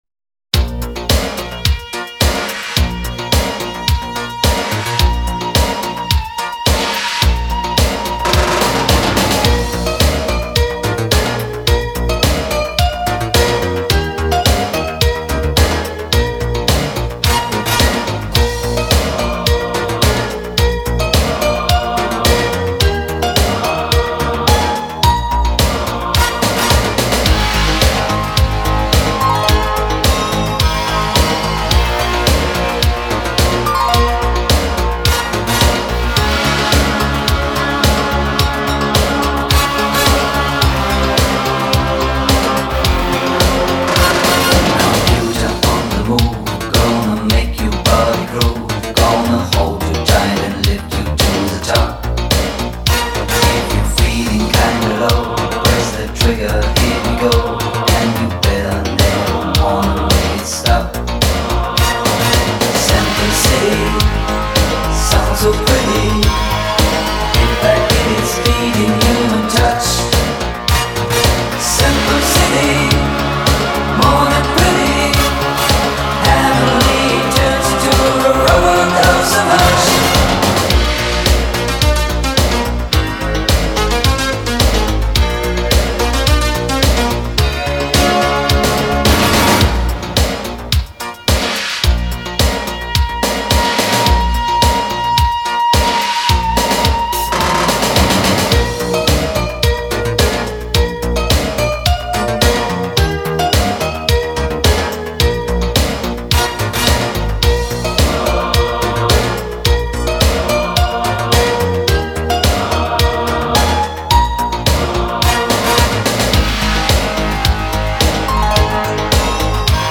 So eighties.